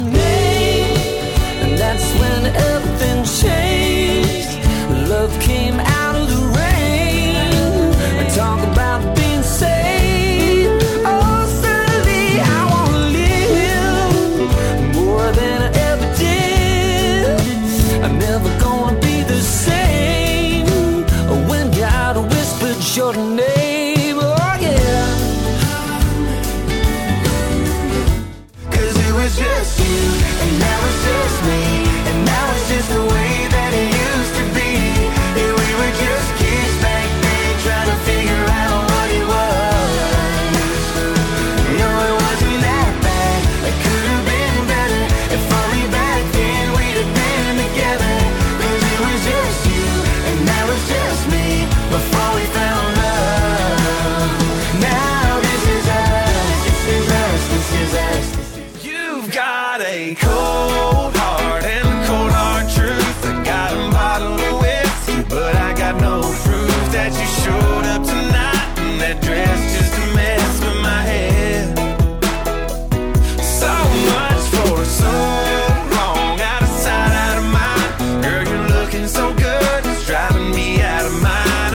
Most Popular Country Songs of The Decade: 2020's